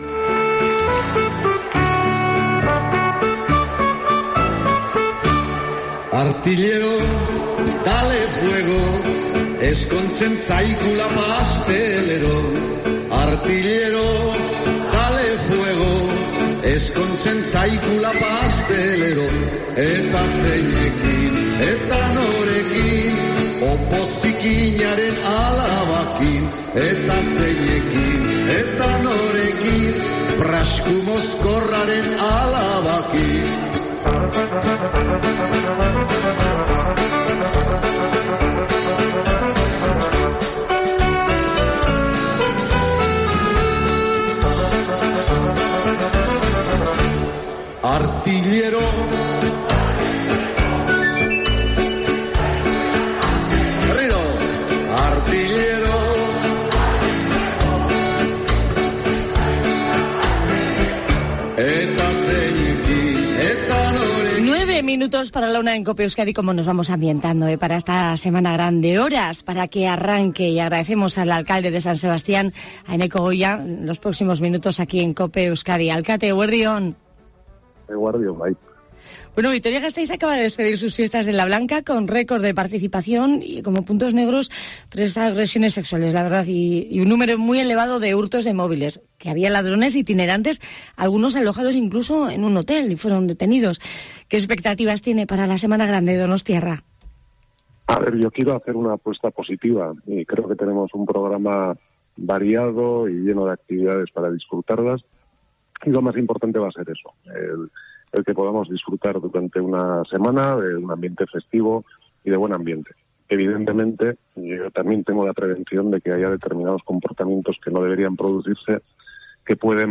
El alcalde de San Sebastián ante la Semana Grande
Entrevista COPE Euskadi Eneko Goia
San Sebastián se prepara para su Semana Grande y en COPE Euskadi hemos tenido una entrevista con el alcalde, Eneko Goia, para hablar sobre el mensaje que lanza el primer edil ante las fiestas y sobre otros asuntos de actualidad.